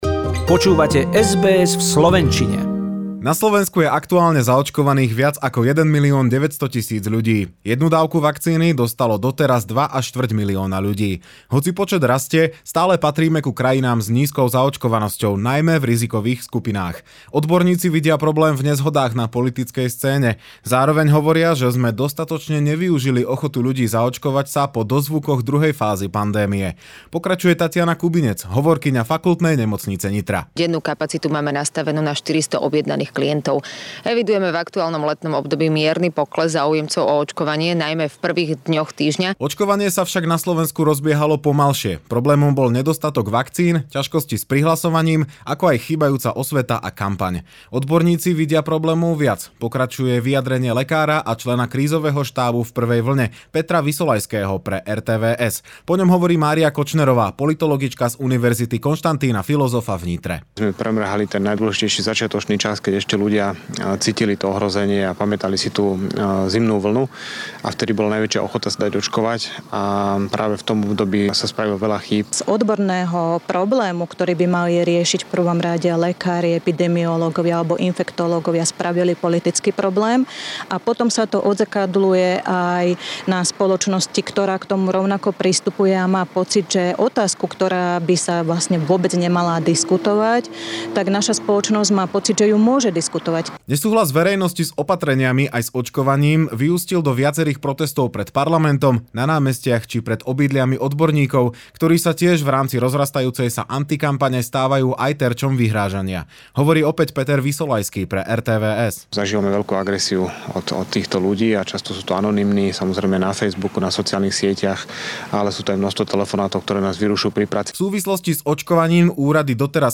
Summary of news from Slovakia as of 1/8/21